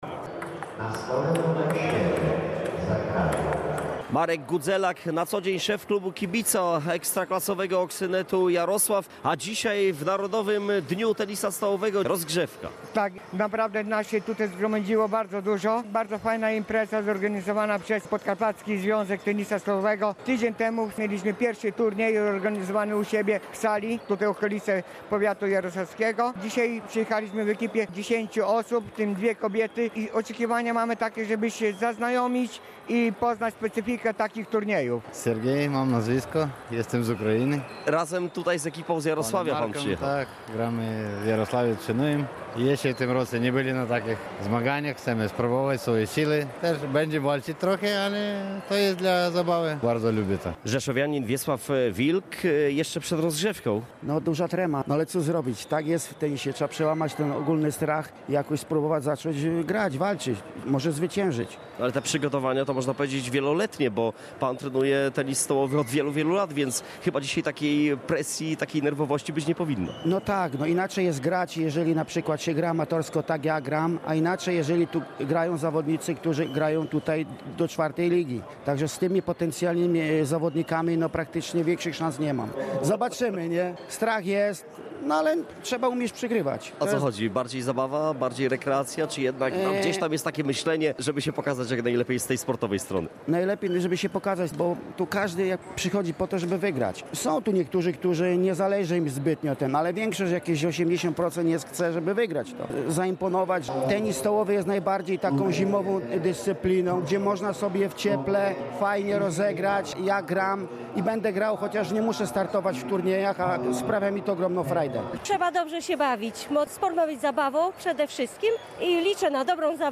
Dziś w szesnastu miastach Polski, od wczesnych godzin przedpołudniowych, w takt tej samej melodii rozlegały się dźwięki odbijanych piłeczek pingpongowych.
Podobnie było w hali rzeszowskiej Politechniki przy ulicy Poznańskiej. Na uczestników Narodowego Dnia Tenisa Stołowego czekały liczne atrakcje: amatorski turniej, spotkania z zawodniczkami ekstraklasowej drużyny Fibrain AZS Politechniki Rzeszów , a także mecze pokazowe, gry i zabawy sprawnościowe.